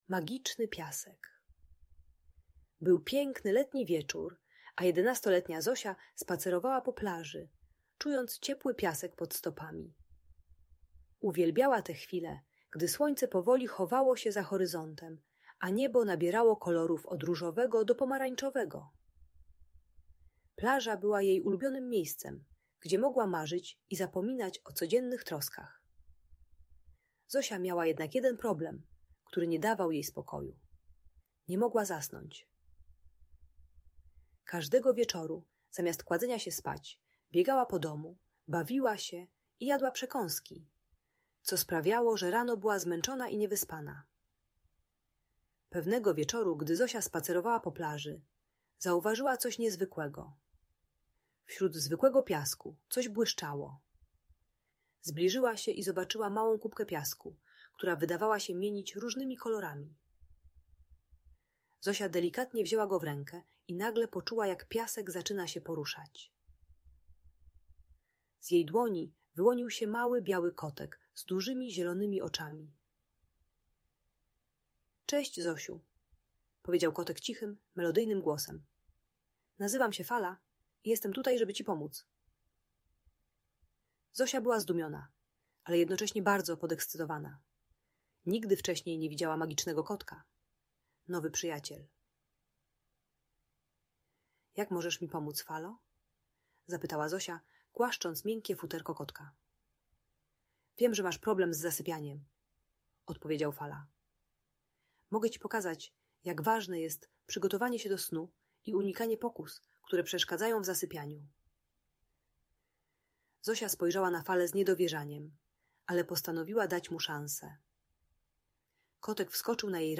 Magiczny Piasek: O Zosi i Fali - Audiobajka